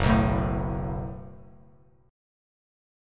F#mbb5 chord